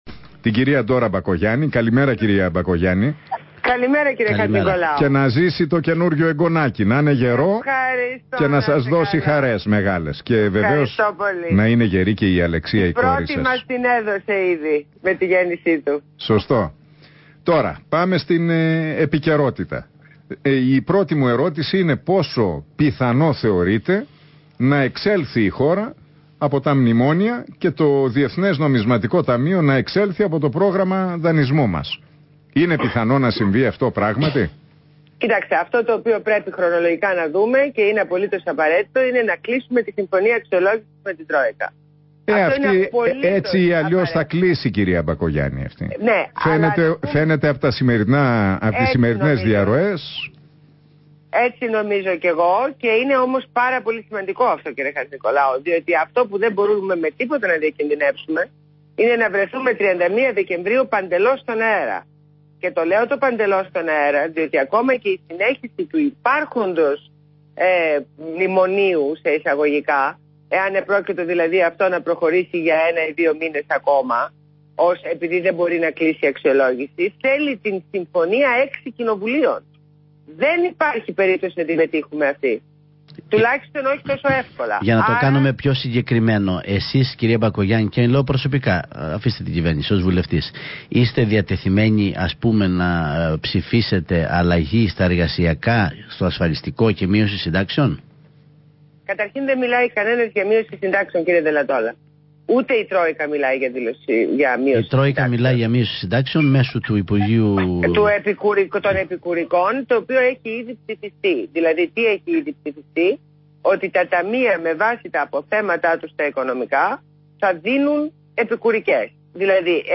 Συνέντευξη στο ραδιόφωνο REAL fm